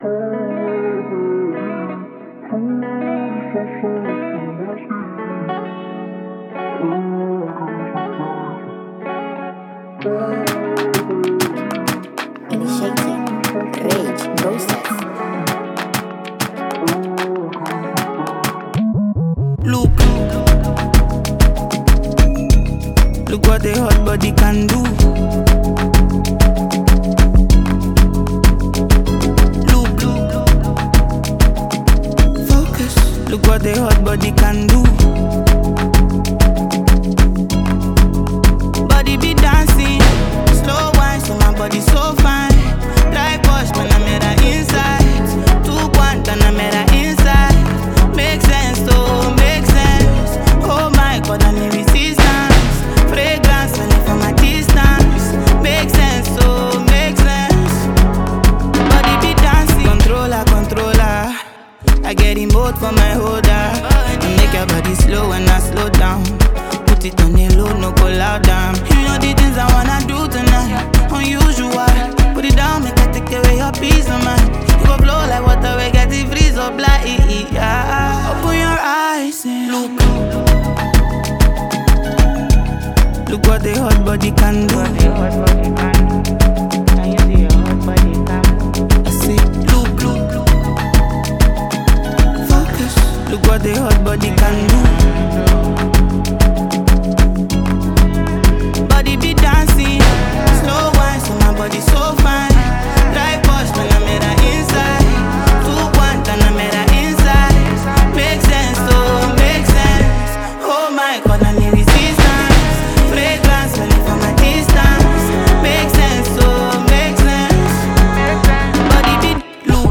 a seasoned Nigerian female singer